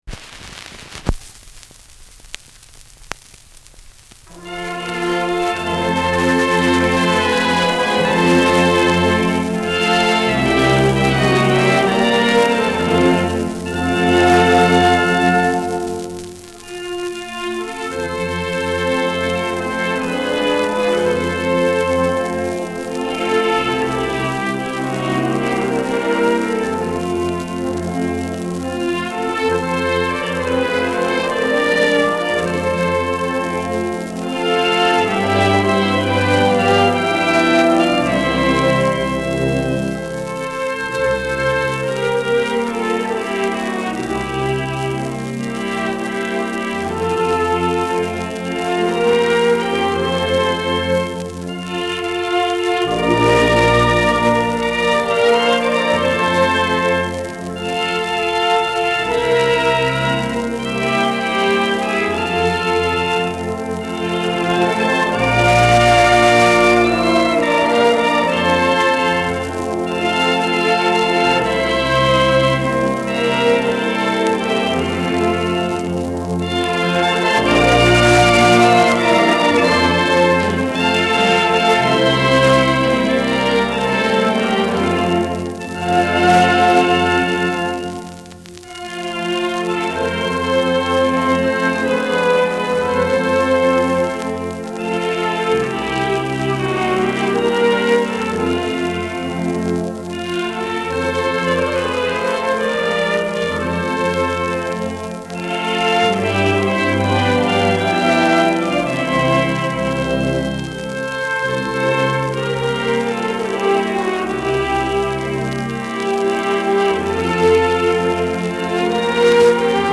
Schellackplatte
Auffällig langsam : Leiern
[Berlin] (Aufnahmeort)